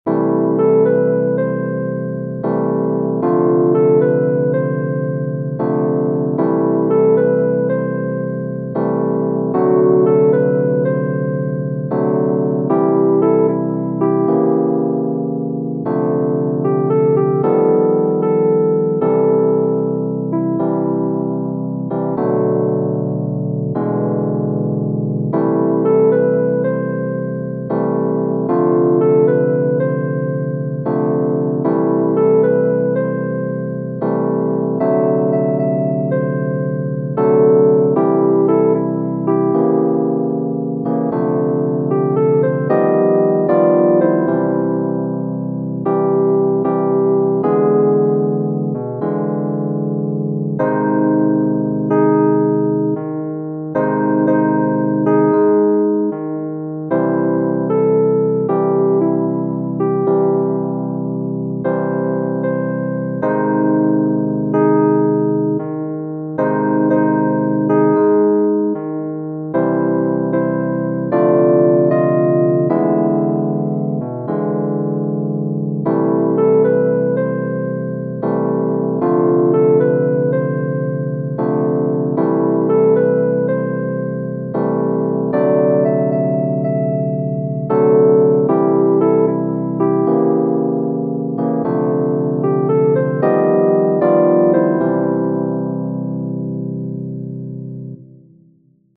Genere: Jazz